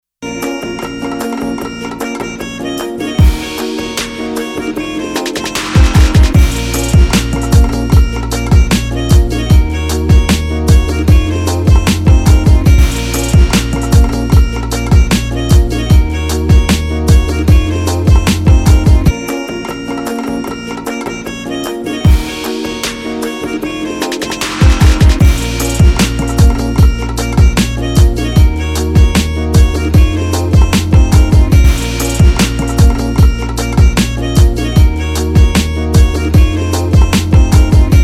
• Качество: 320, Stereo
без слов
Вырезка с песни